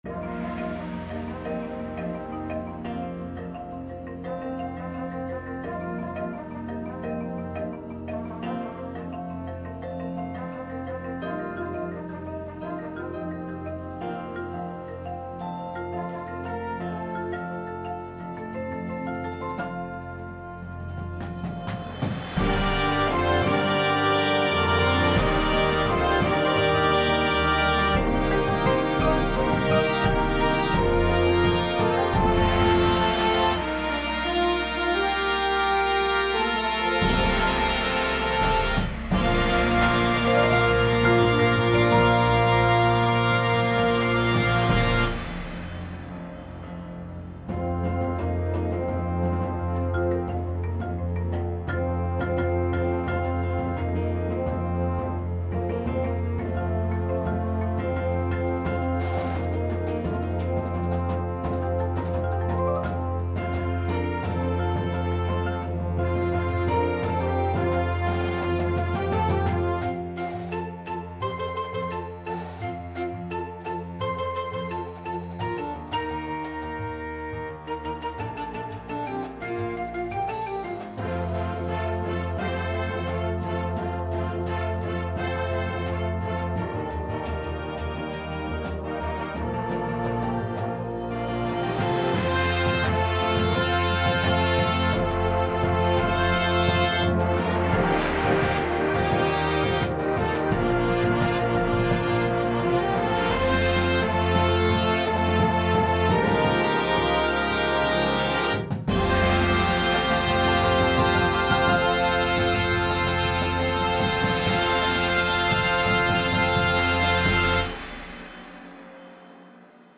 • Flute
• Horn in F
• Trombone
• Tuba
• Snare Drum
• Synthesizer
• Glockenspiel